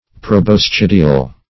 Proboscidial \Pro`bos*cid"i*al\, a.